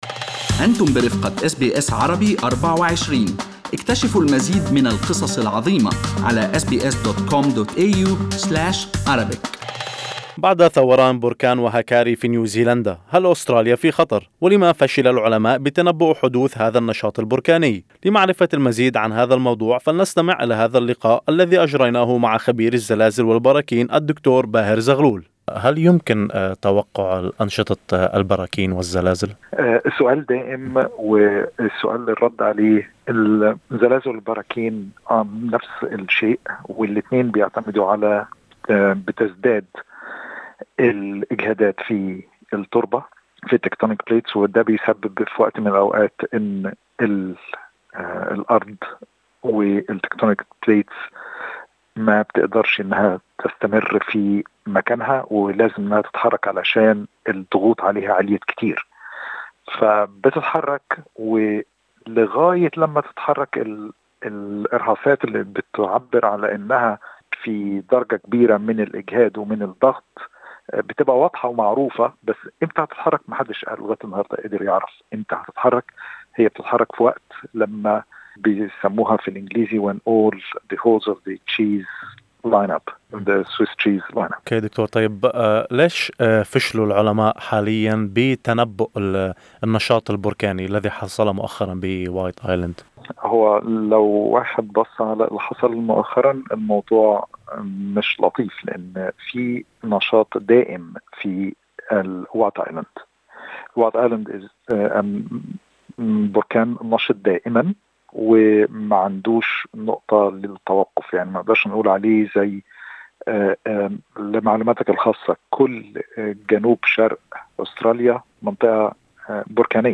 لمعرفة المزيد.. استمعوا إلى اللقاء كاملا عبر الضغط على الملف الصوتي أعلاه.